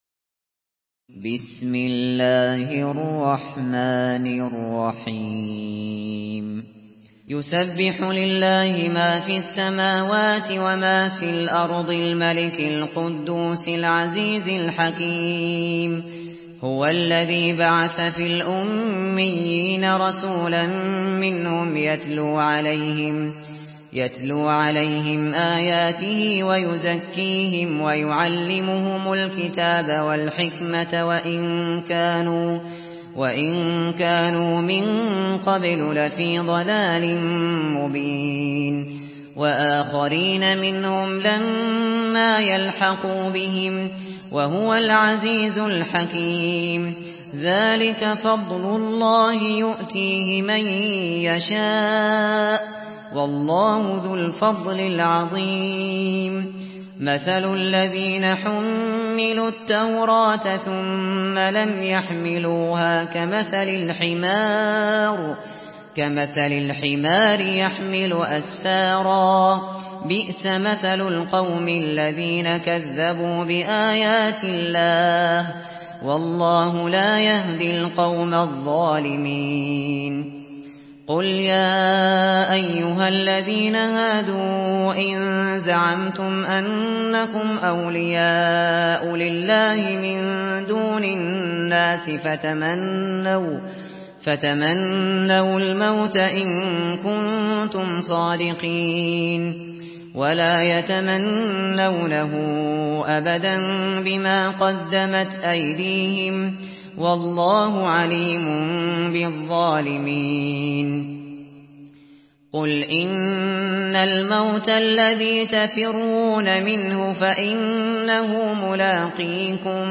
Surah Al-Jumuah MP3 in the Voice of Abu Bakr Al Shatri in Hafs Narration
Murattal Hafs An Asim